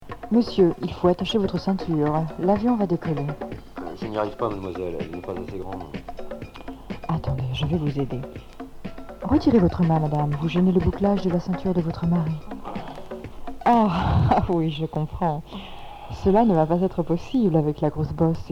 Punk rock Deuxième 45t retour à l'accueil